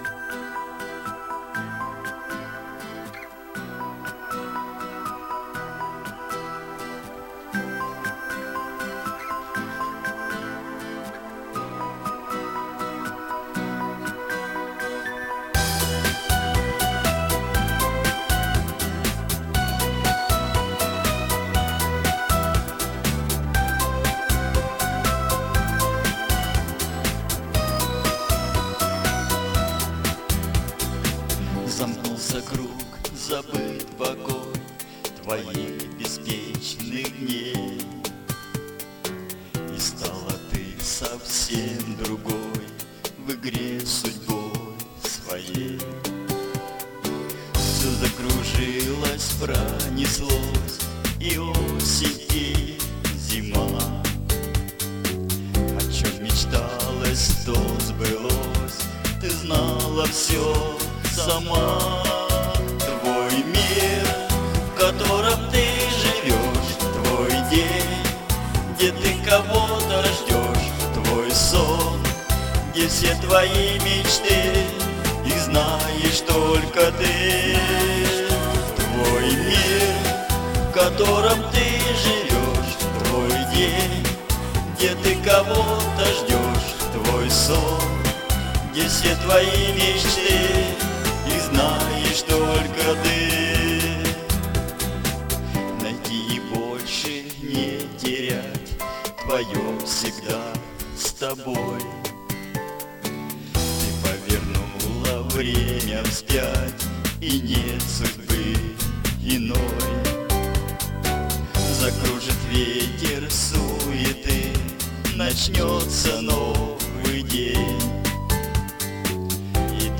Тут просто дуэт как бы сам с собой,Это чтоб не гадала.